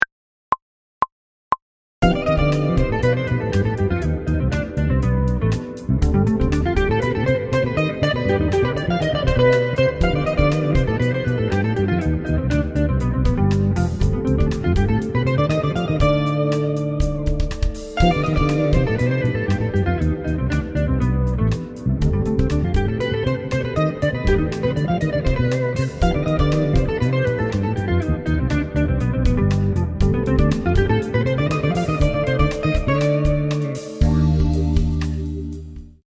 Näide nr 5 (120 BPM):